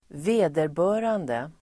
Ladda ner uttalet
Uttal: [²v'e:derbö:rande]